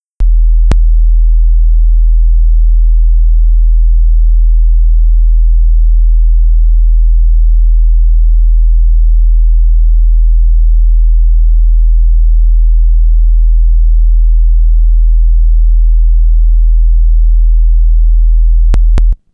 40.6Hz.wav